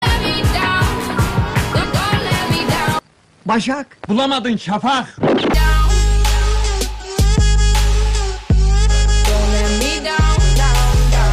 telefon-zil-sesleri-kopmalk-5-audiotrimmer.mp3